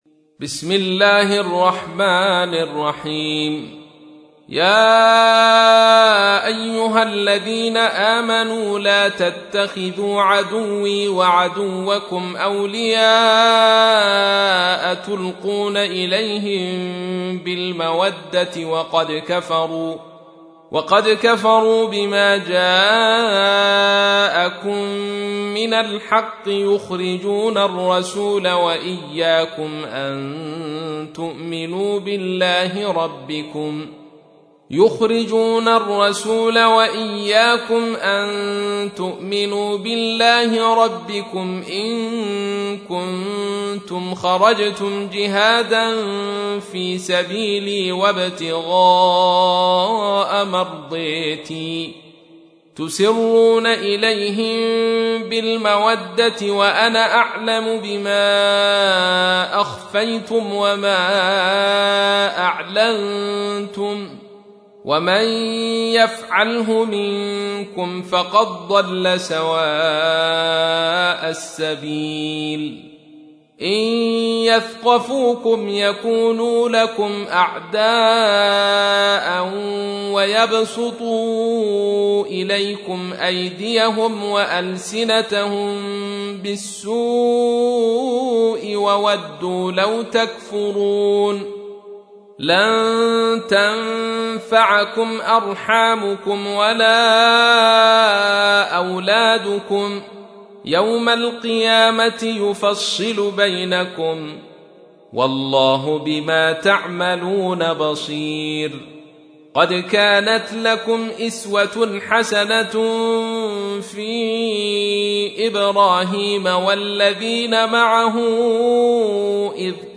تحميل : 60. سورة الممتحنة / القارئ عبد الرشيد صوفي / القرآن الكريم / موقع يا حسين